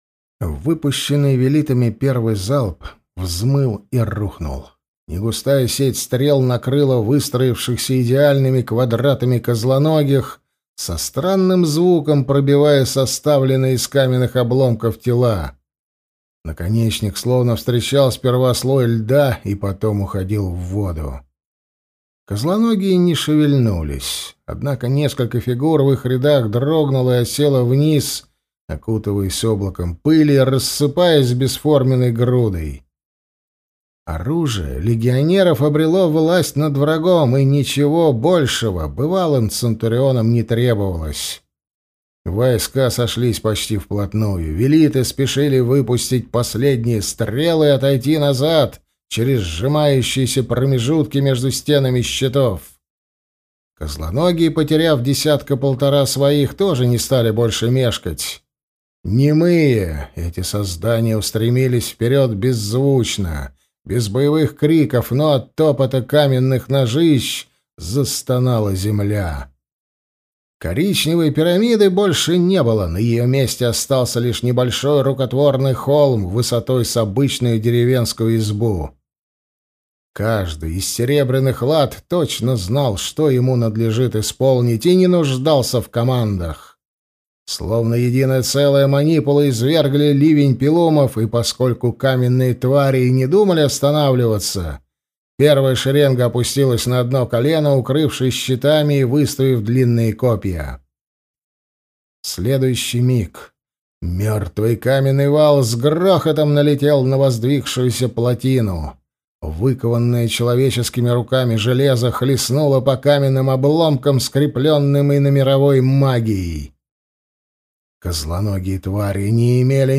Аудиокнига Война мага. Том 4. Конец игры. Часть 1 | Библиотека аудиокниг